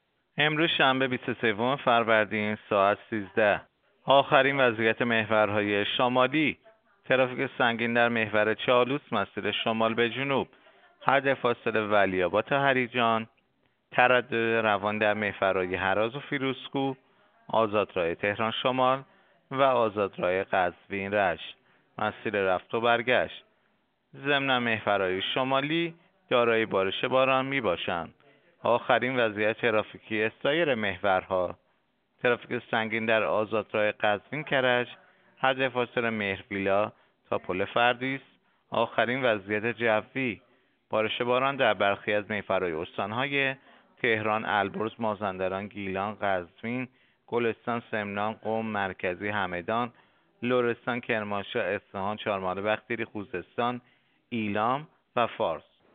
گزارش رادیو اینترنتی از آخرین وضعیت ترافیکی جاده‌ها ساعت ۱۳ بیست و سوم فروردین؛